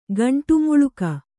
♪ gaṇṭu muḷuka